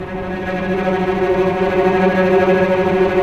Index of /90_sSampleCDs/Roland LCDP08 Symphony Orchestra/STR_Vcs Bow FX/STR_Vcs Trem p